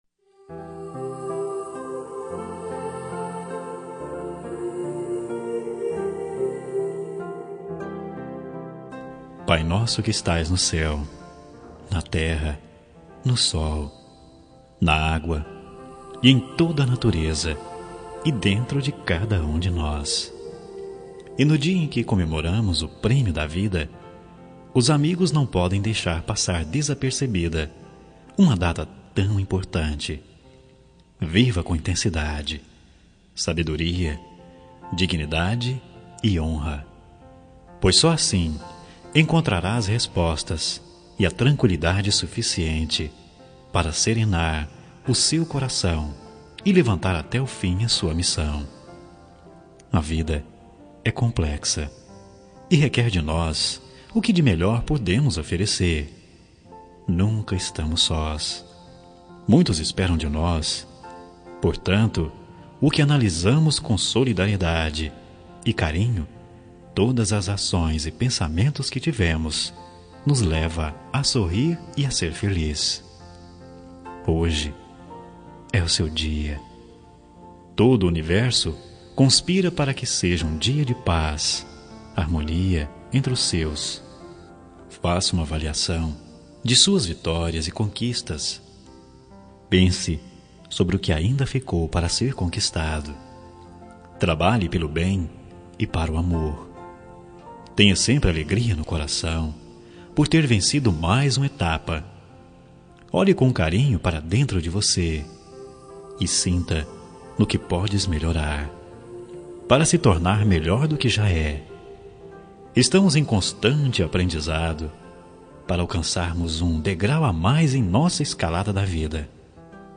Aniversário Religioso – Voz Masculina – Cód: 34886